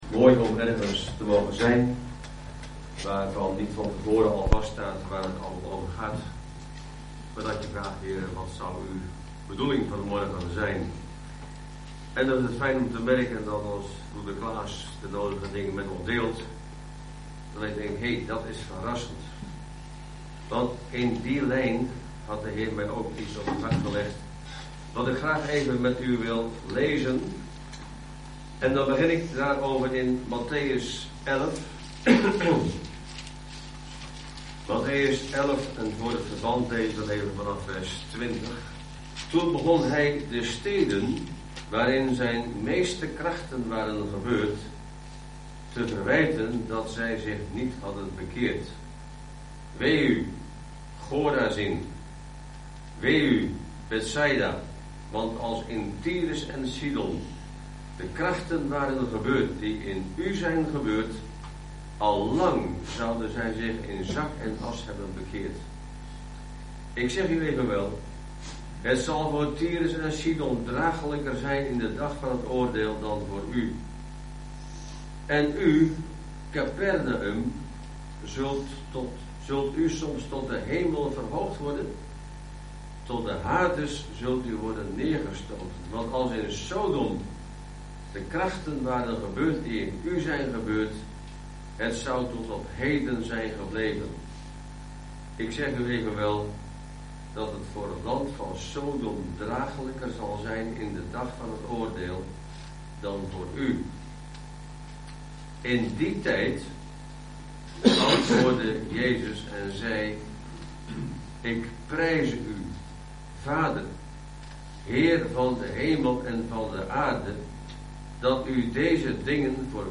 Over deze preek